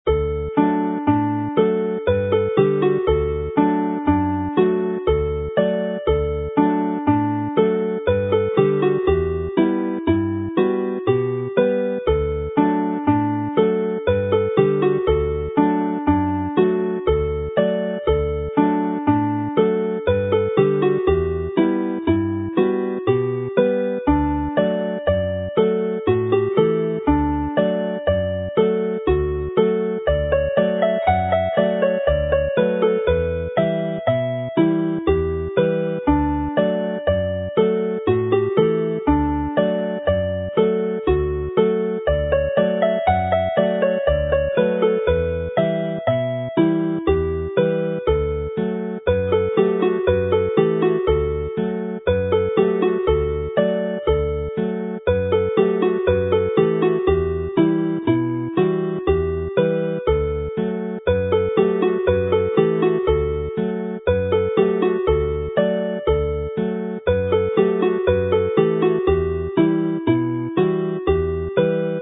Alawon Cymreig - Set Gŵr a'i Farch - Welsh folk tunes to play
Seilir y set yma ar Gŵr a'i Farch sy'n bibddawns 3/2 ond mae'r set gyfan yn rhedeg fel gorymdaith neu polca.
This set is based on a 3/2 hornpipe but the whole set runs as a set of marches or polkas.
Jig Arglwydd Caernarfon is presented in D here; it is also in this collection played as a jig  but is normally played in 4/4 time and usually in G.